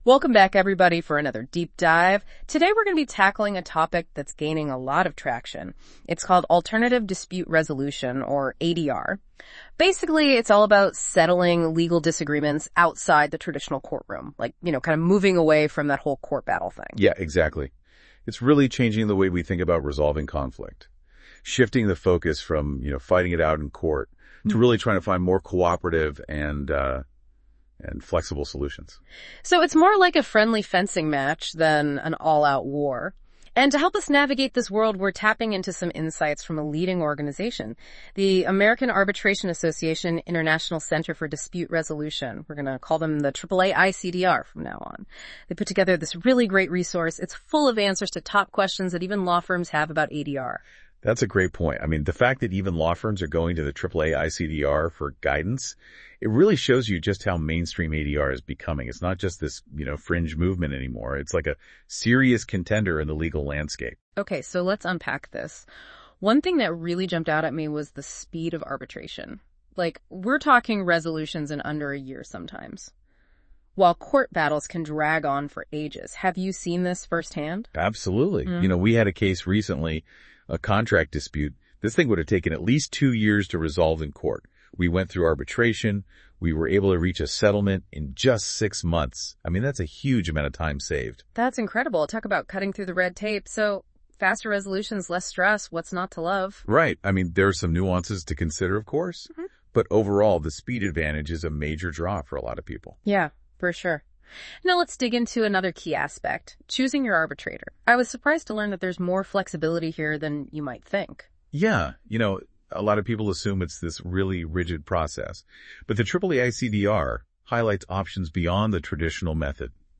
Click here to listen to the AI-generated podcast summary of this article.